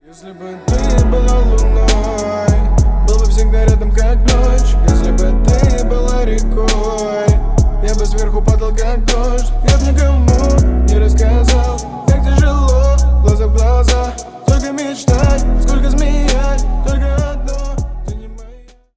• Качество: 320 kbps, Stereo
Поп Музыка
грустные